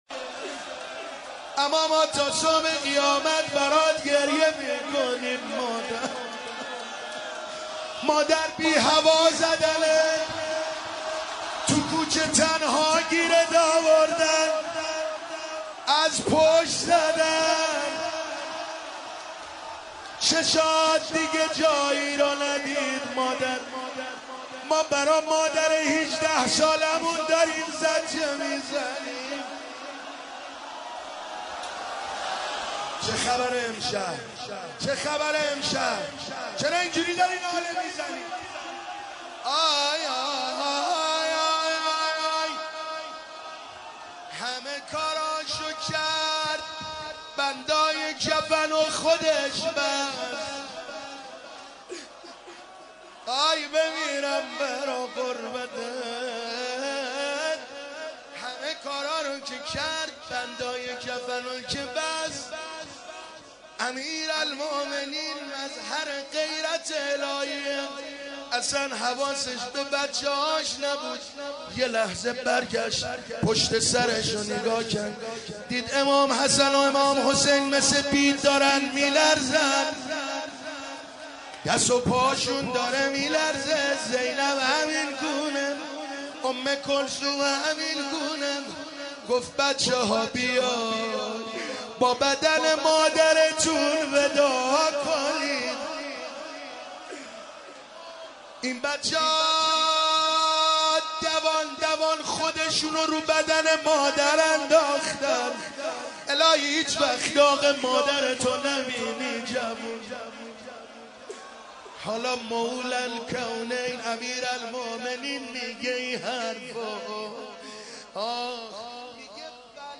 مداحی و نوحه
روضه خوانی، شهادت حضرت فاطمه زهرا(س